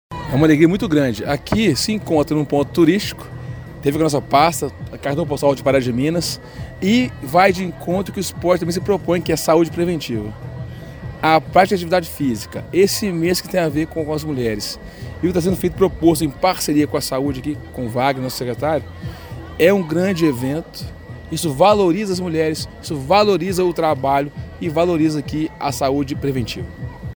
O vice-prefeito e secretário municipal de Esporte, Lazer e Turismo, Paulo Francisdale, afirma ser uma grande alegria trabalhar esta valorização das mulheres e da saúde preventiva: